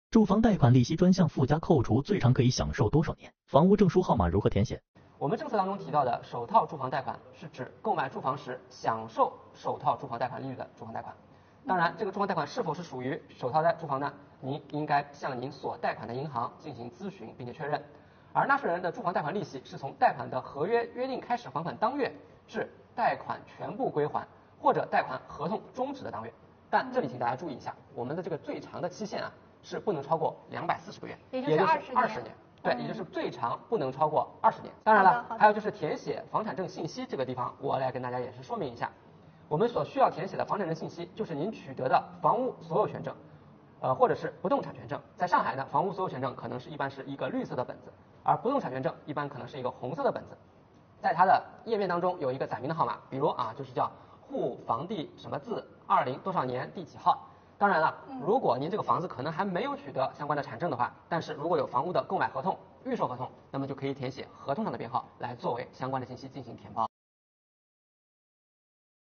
别着急，点击视频让主播来告诉您~